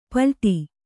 ♪ palṭi